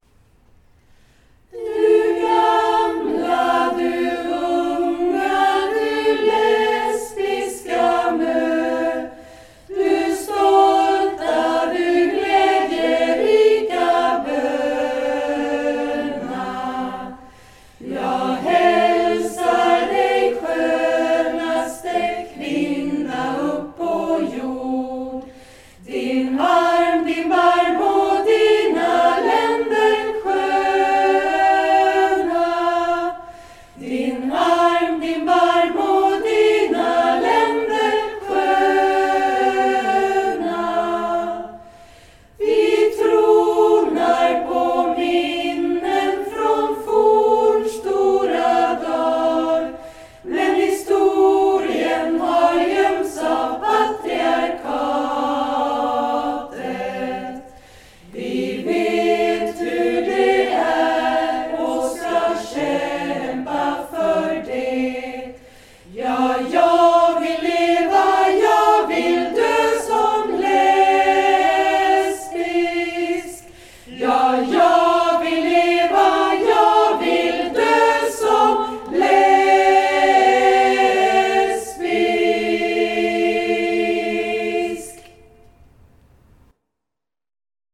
women’s choir